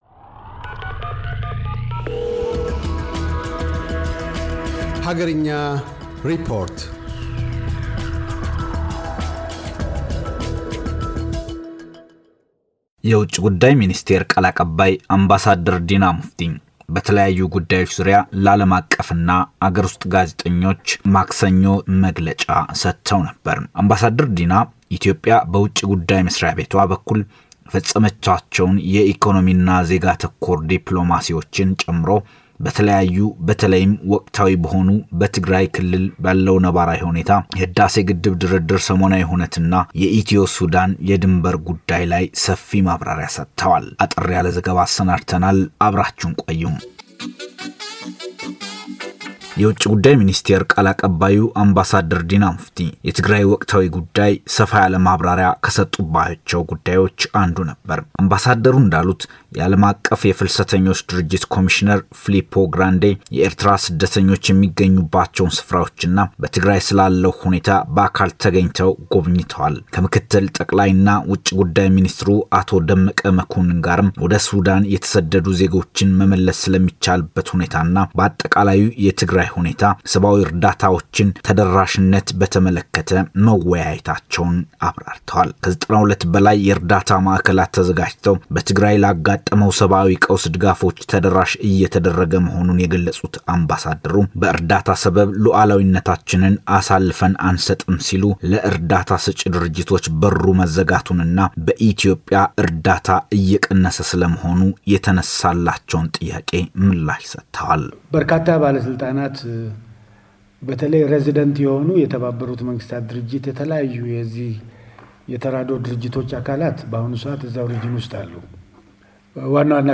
አገርኛ ሪፖርት - የኢትዮጵያ ውጭ ጉዳይ ሚኒስቴር ቃል አቀባይ አምባሳደር ዲና ሙፍቲ፤ ስለ ወቅታዊው የትግራይ ሁኔታ፣ የኢትዮጵያ - ሱዳን ድንበር ውዝግብና ከአዲሱ የፕሬዚደንት ጆ ባይደን አስተዳደር ጋር የኢትዮጵያን ቀጣይ የውጭ ግኝኑነት አስመልክቶ የሰጡትን ማብራሪያ ያነሳል።